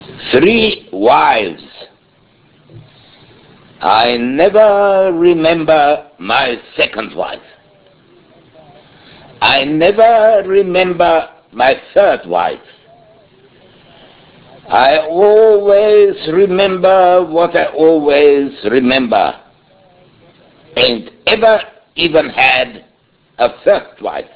Ernst Jandl O-Ton